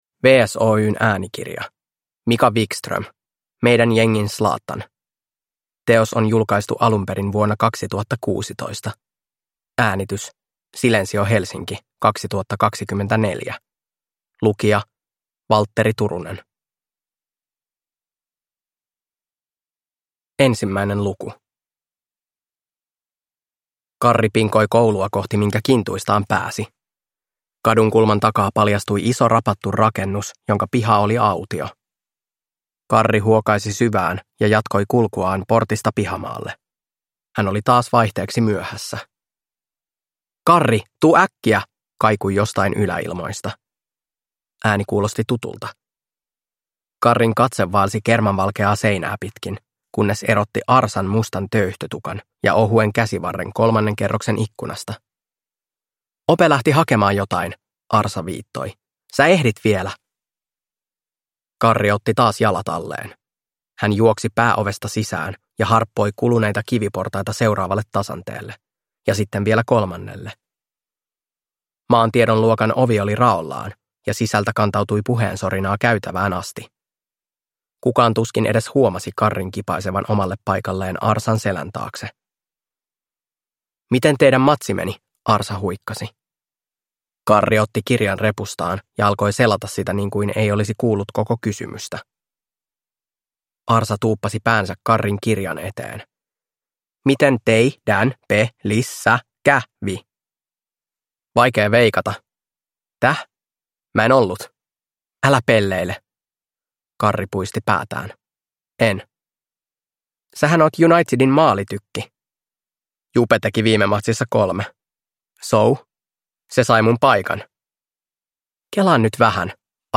Meidän jengin Zlatan – Ljudbok